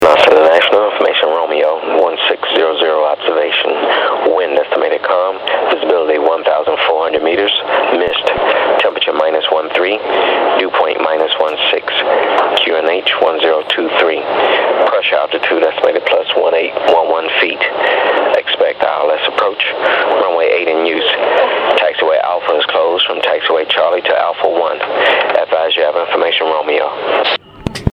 Начало » Записи » Записи радиопереговоров - авиация
Запись метеоинформации частоты АТИС для контингента НАТО аэропорта совместного базирования Манас (г.Бишкек, Киргизия) 24.12.11г на частоте 128.700 МГц